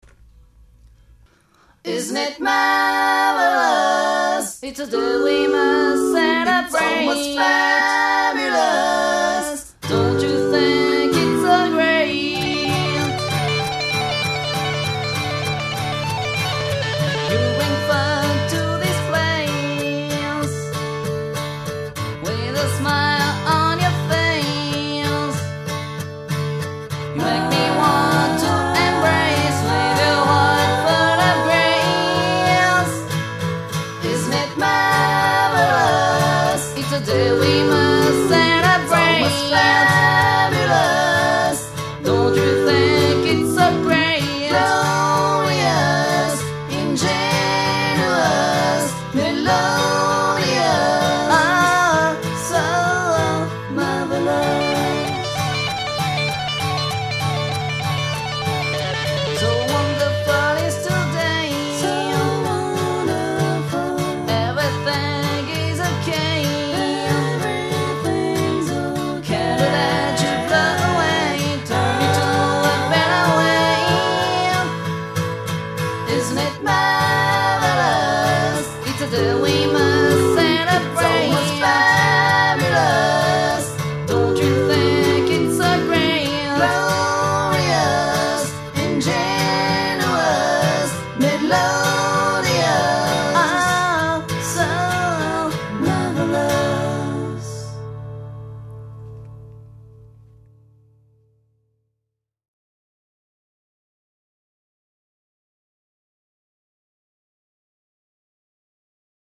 アコースティックな曲が書きたくてできた曲。12弦を使ってみたらなんかカントリーっぽい音になった気が・・・